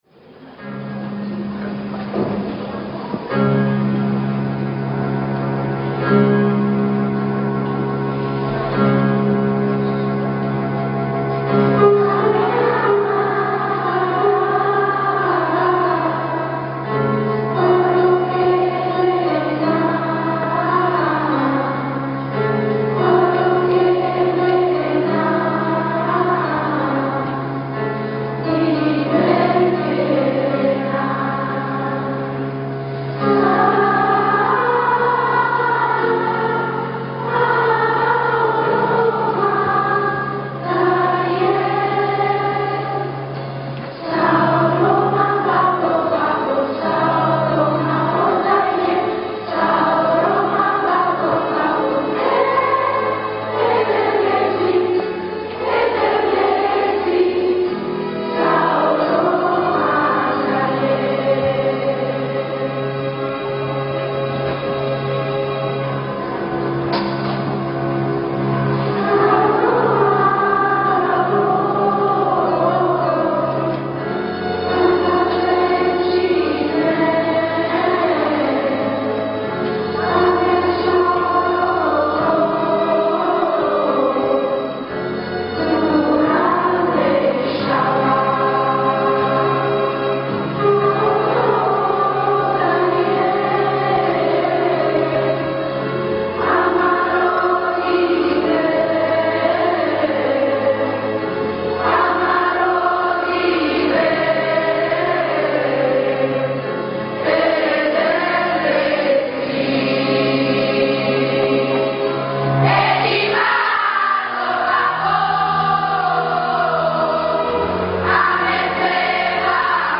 GenereCori